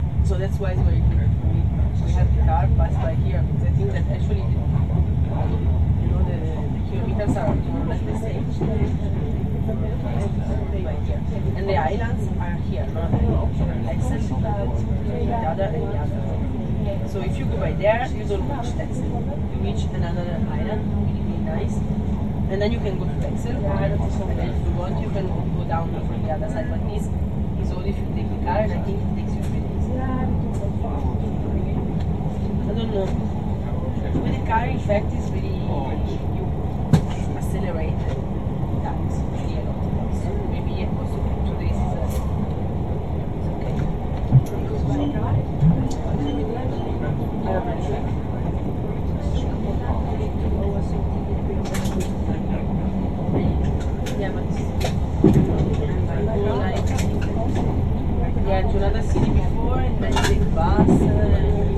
火车对话 - 声音 - 淘声网 - 免费音效素材资源|视频游戏配乐下载
录音：quot;火车上的对话，我觉得是西班牙口音的。LoFi录音，有很多来自火车和其他人谈话的背景噪音，有很多 quot;鸡尾酒会 quot;的效果